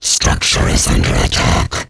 alien_structureattack1.wav